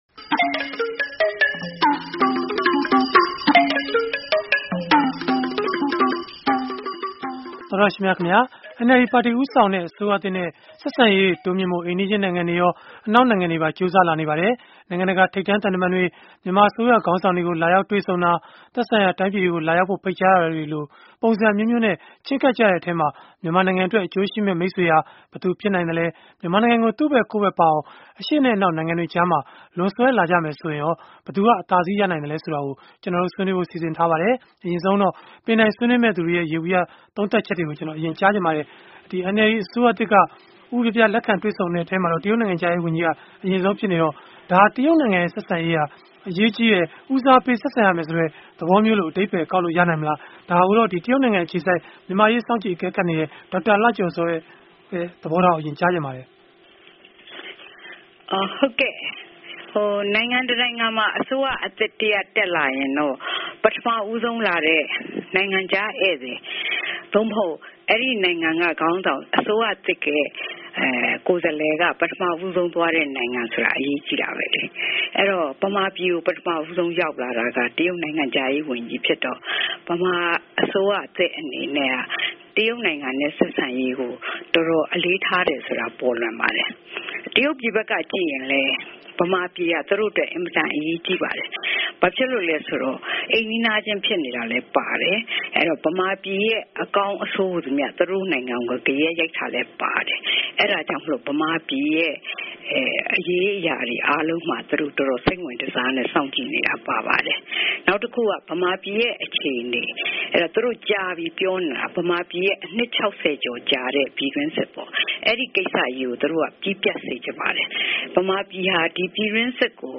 NLD အစိုးရသစ်နဲ့ နိုင်ငံရေး၊ သံတမန်ရေး၊ စီးပွားရေး အဆက်အဆံတွေ တိုးမြှင့်ဖို့ ပုံစံအမျိုးမျိုးနဲ့ ချဉ်းကပ်နေကြတဲ့ အရှေ့နဲ့အနောက် နိုင်ငံတွေကြား မြန်မာနိုင်ငံအတွက် အကျိုးရှိမယ့် မိတ်ဆွေဟာ ဘယ်သူဖြစ်နိုင်ပါသလဲ ဆိုတာကို အင်္ဂါနေ့ည တိုက်ရိုက်လေလှိုင်း အစီအစဉ်မှာ ဆွေးနွေးထားပါတယ်။